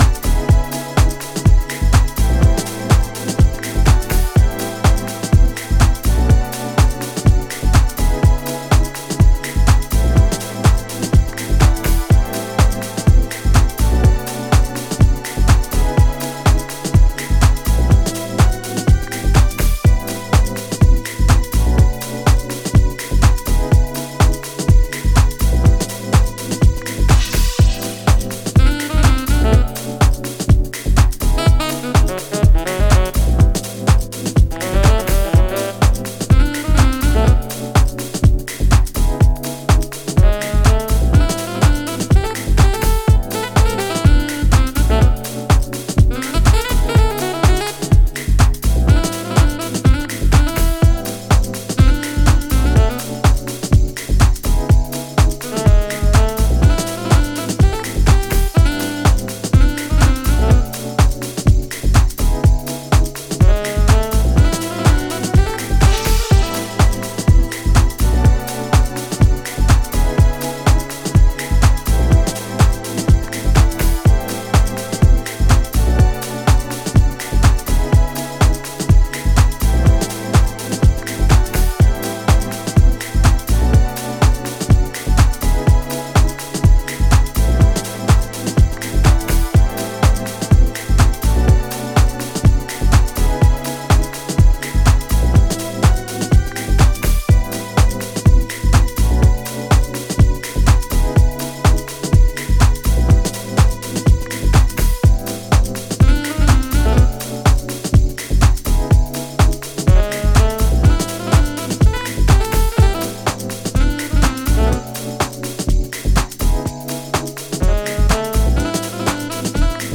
Styl: Disco, House, Breaks/Breakbeat